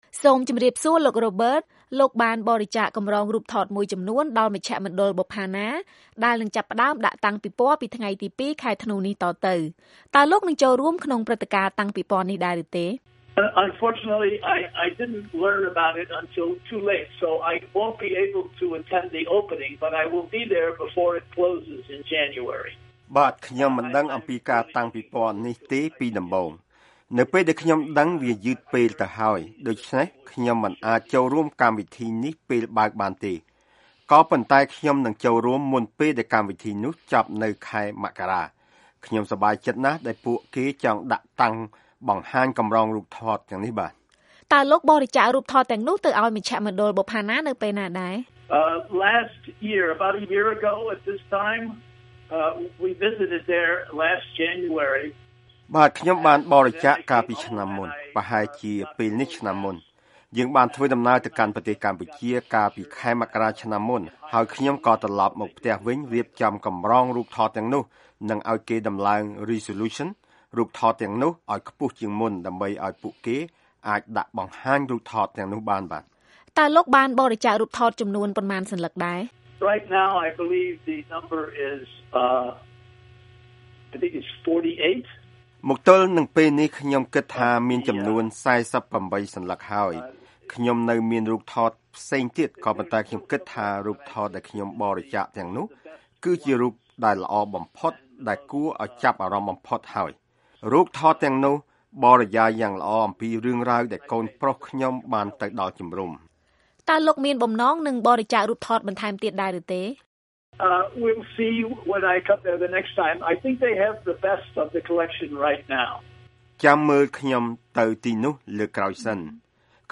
បទសម្ភាសន៍ VOA៖ កម្រងរូបថតជំរំជនភៀសខ្លួននឹងដាក់តាំងពិព័រណ៍ឲ្យកូនខ្មែរបានដឹងពីប្រវត្តិសាស្រ្ត